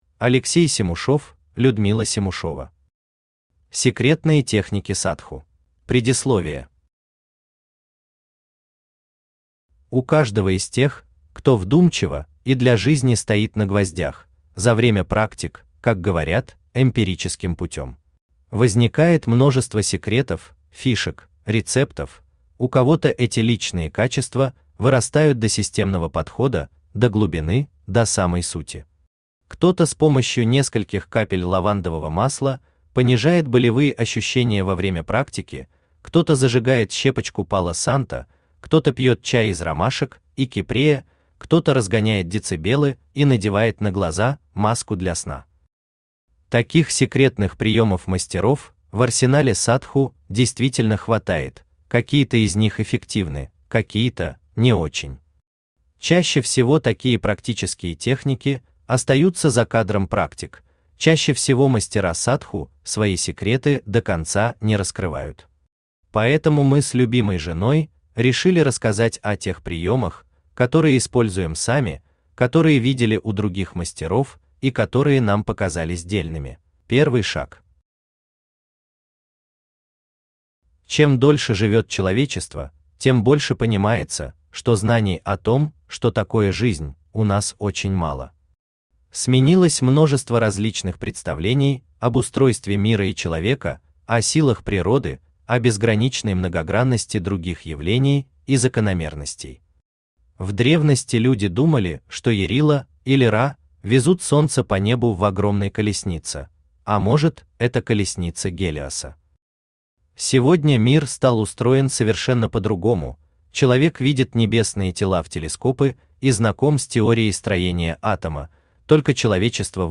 Аудиокнига Секретные техники Садху | Библиотека аудиокниг
Aудиокнига Секретные техники Садху Автор Алексей Семушев Читает аудиокнигу Авточтец ЛитРес.